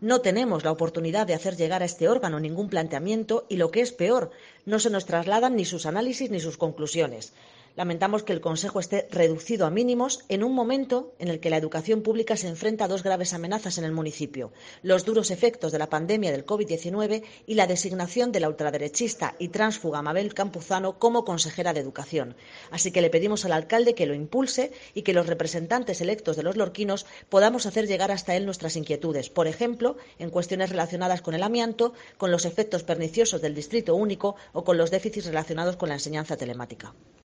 Gloria Martín, edil de IU Verdes en Ayto Lorca